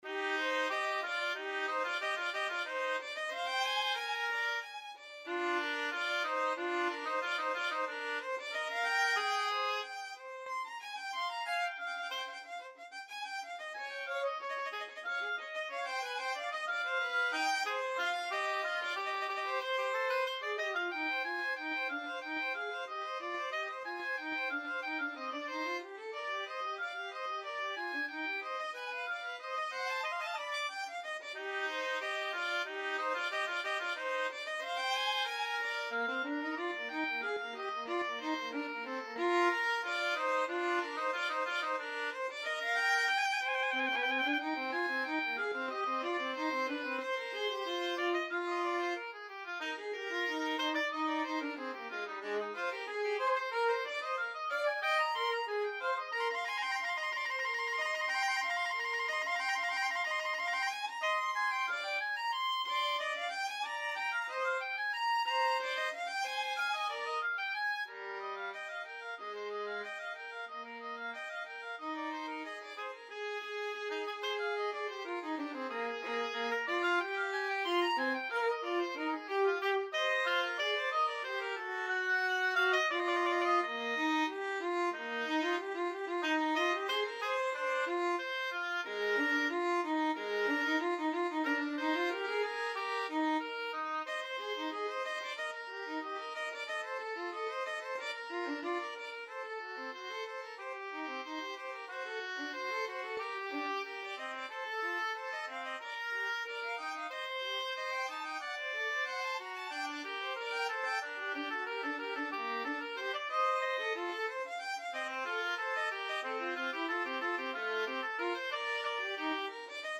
Allegro =c.92 (View more music marked Allegro)
Classical (View more Classical Oboe-Violin Duet Music)